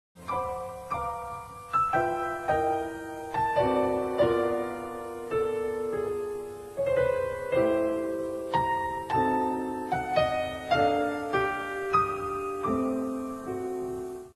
Kleines Konzert für Klavier und Orchester in 5 Sätzen